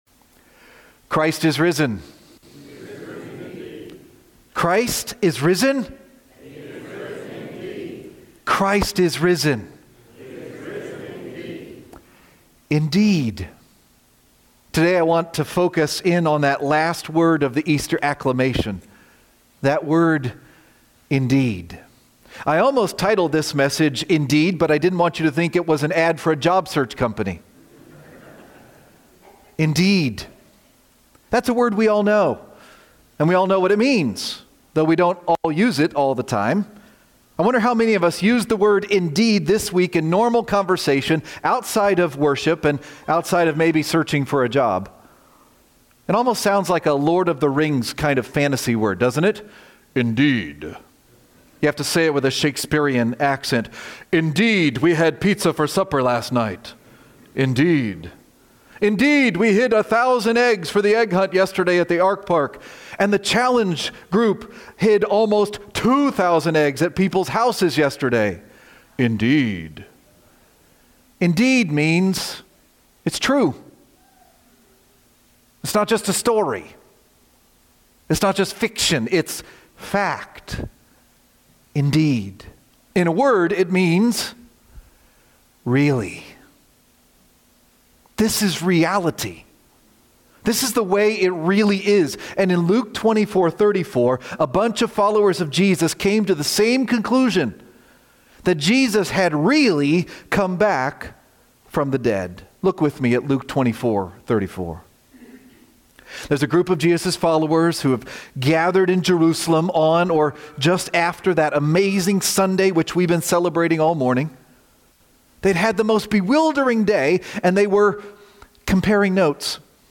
Really! :: April 5, 2026 - Lanse Free Church :: Lanse, PA